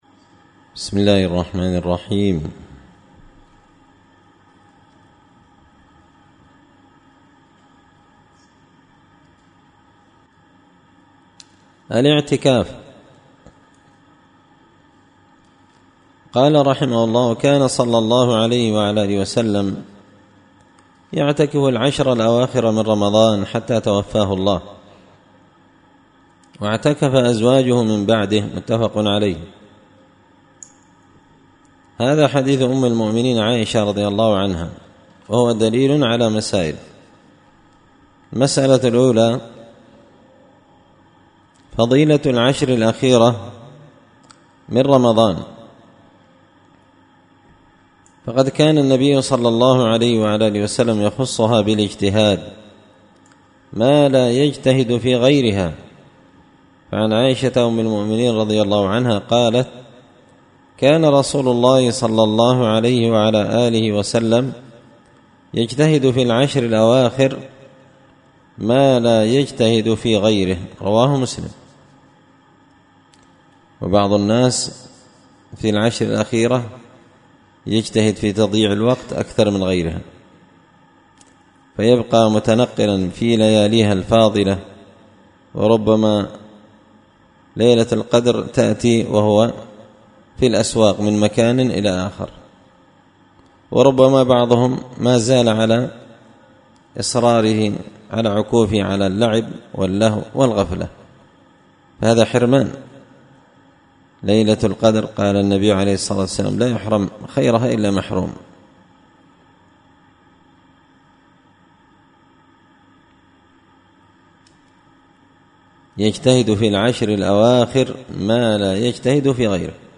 منتقى الأفنان في فقه الصوم وأعمال رمضان الدرس العشرون